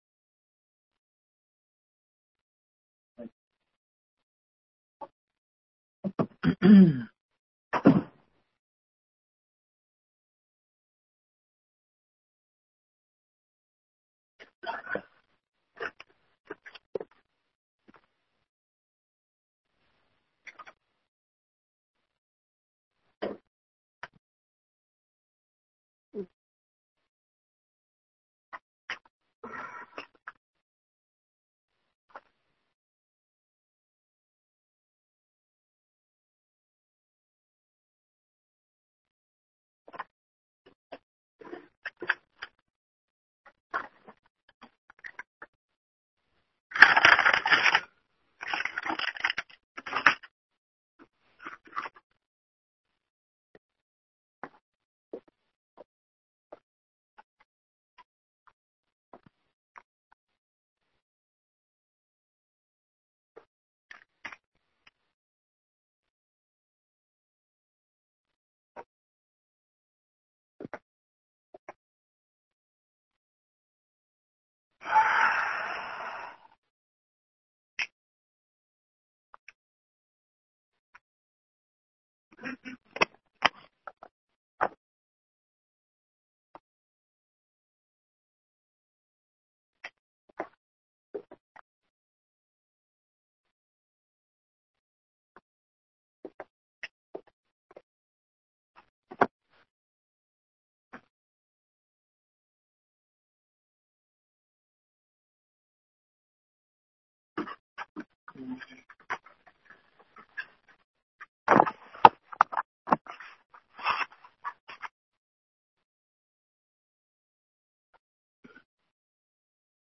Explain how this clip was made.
Townhall meeting – in review